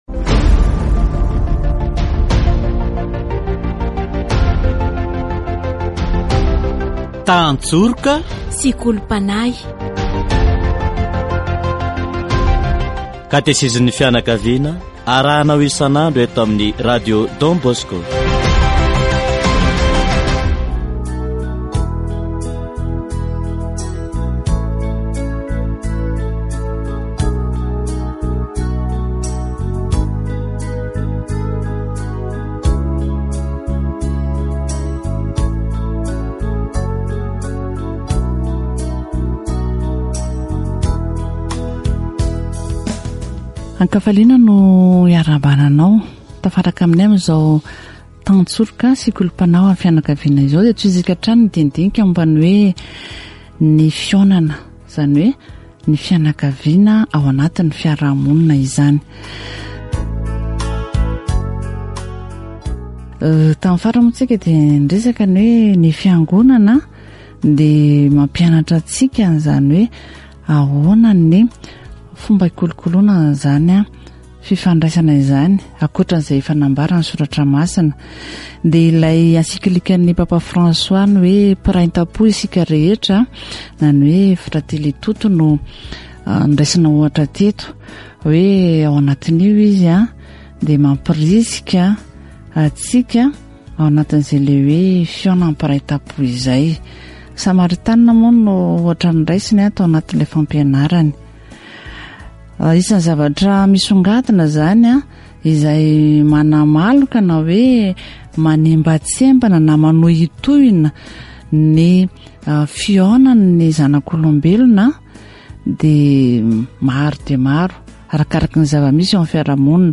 Le pardon est une bonne base pour une relation. Catéchèse sur la rencontre, l'échange d'idées